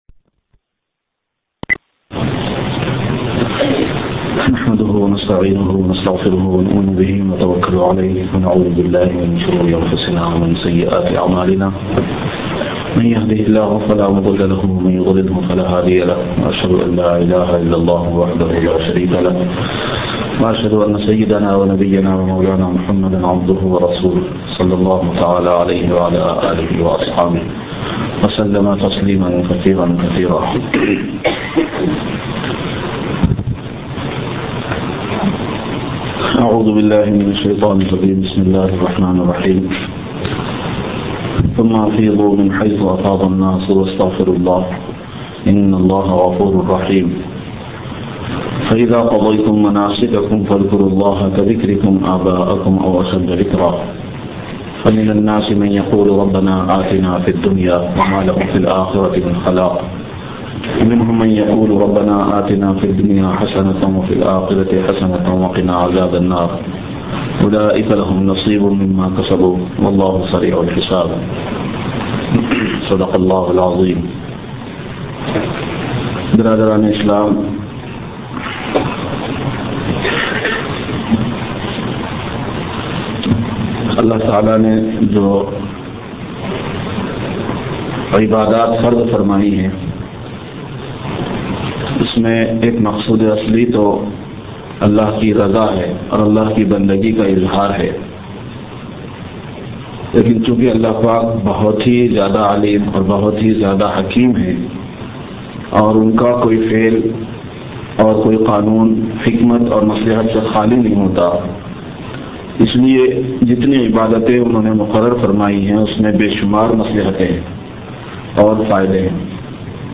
(Jum'ah Bayan)